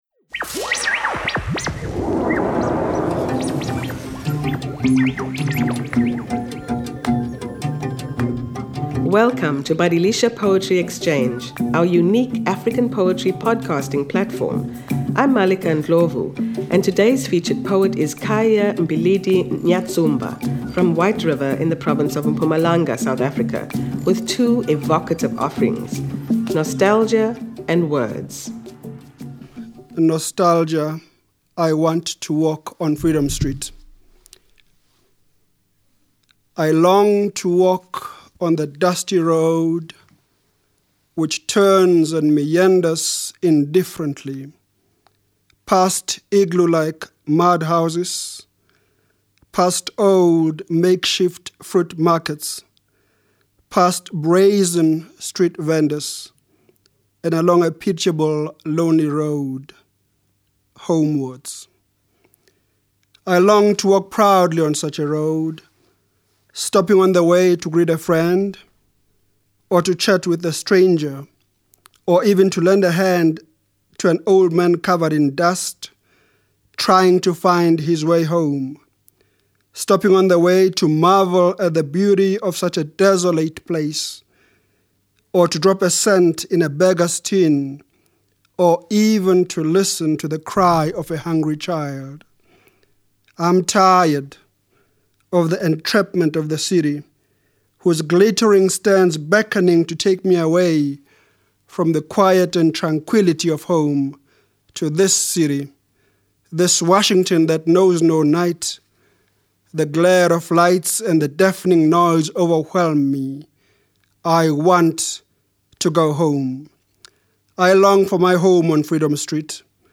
Poetry Readings